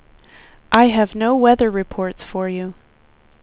speech / tts / prompts / voices